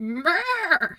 Animal_Impersonations
sheep_2_baa_calm_03.wav